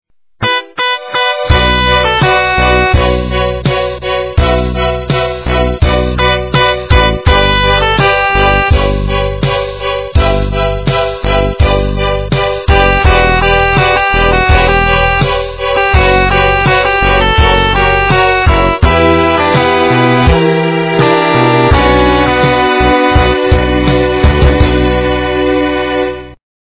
качество понижено и присутствуют гудки.
полифоническую мелодию